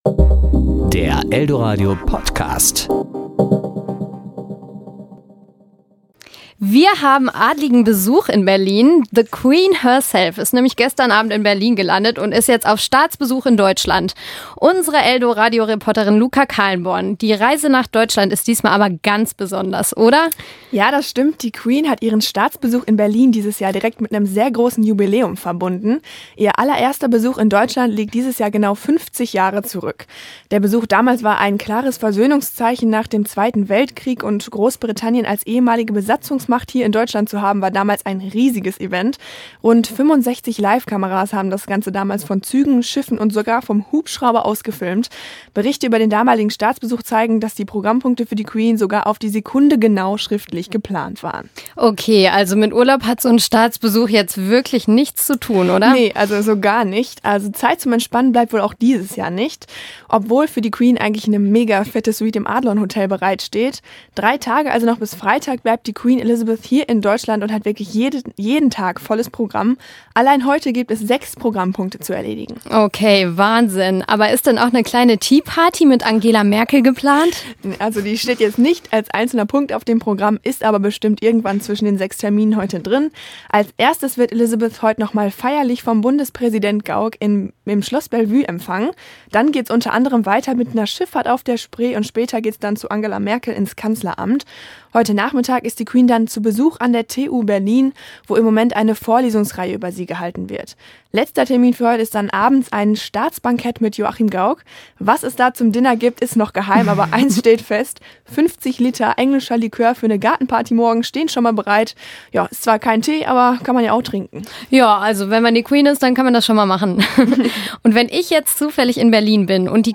Kollegengespräch  Ressort